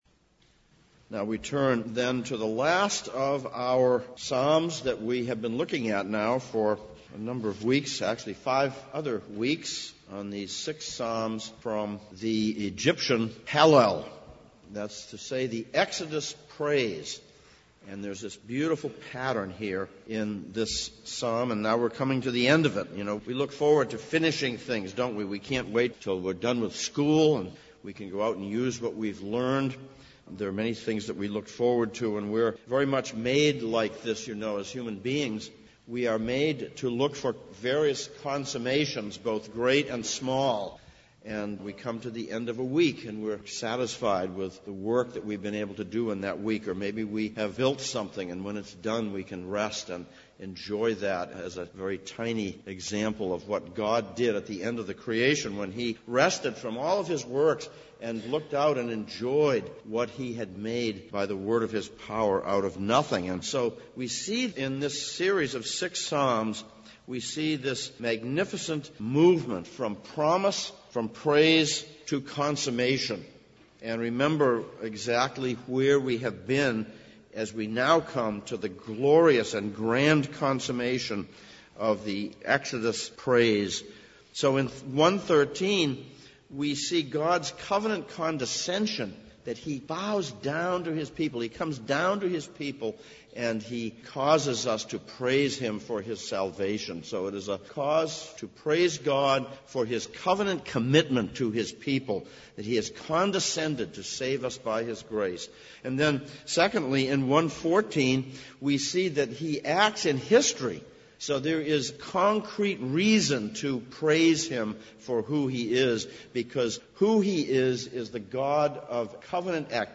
2016 Passage: Psalm 118:1-29, 1 Peter 2:1-12 Service Type: Sunday Morning « 19.